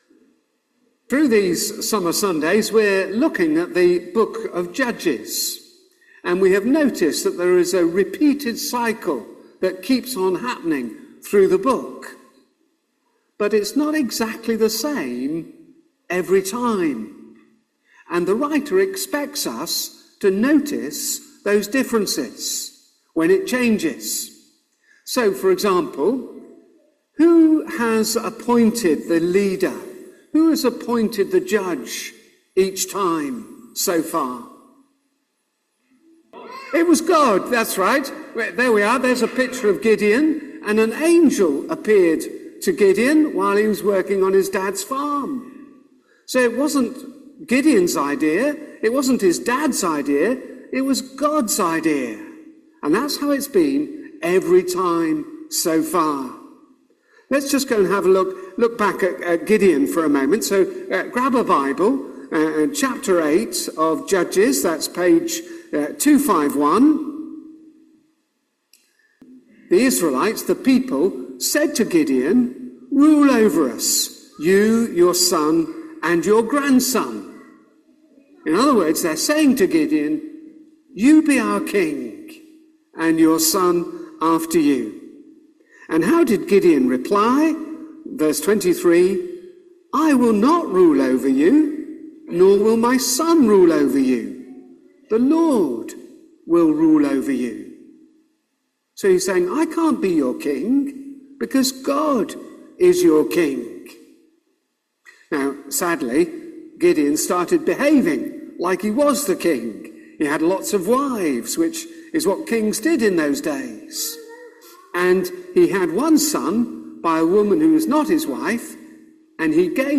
All Age section to 15.12; reading starts at 7.37; main talk starts at 15.12